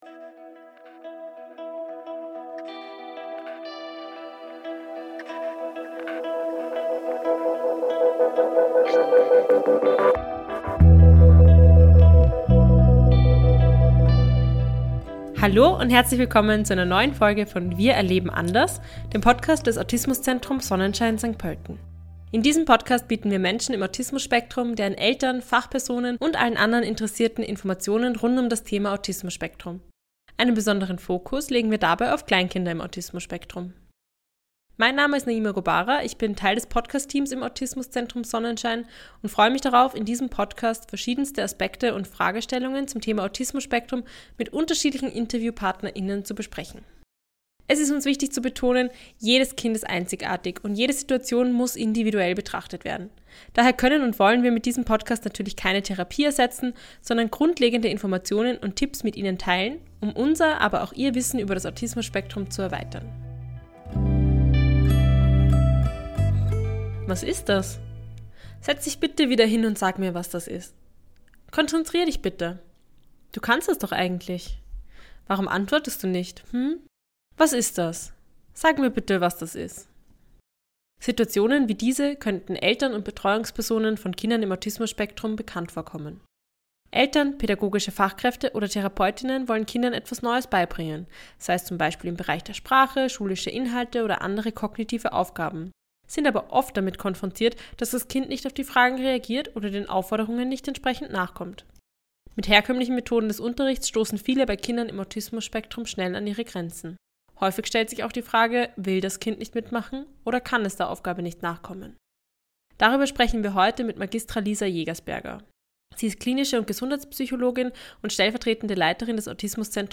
Lernen macht Spaß - Wie man Kindern im Autismus-Spektrum Neues beibringen kann. Ein Gespräch